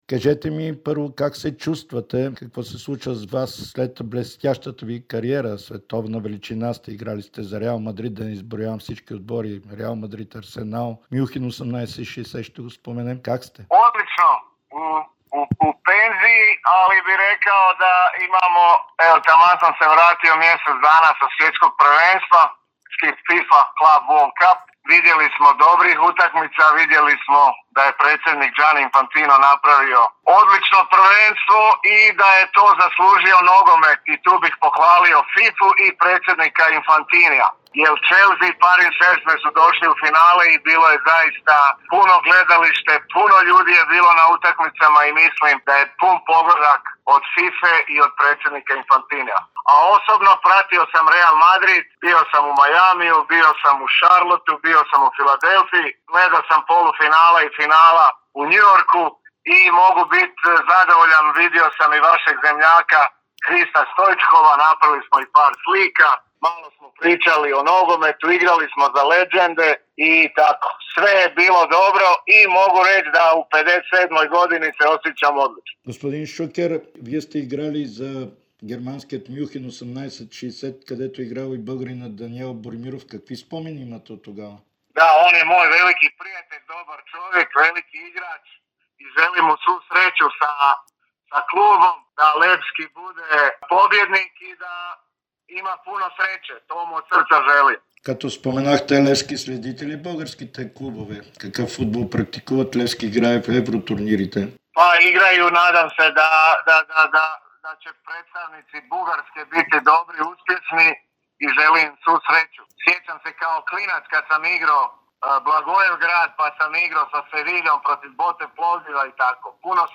Хърватската футболна легенда Давор Шукер ексклузивно пред Дарик радио и Dsport пожела успех на Левски в предстоящите мачове срещу Спортинг Брага в Лига Европа. Той разкри, че може да присъства на първия мач в София, който е в четвъртък, тъй като е получил покана от Даниел Боримиров.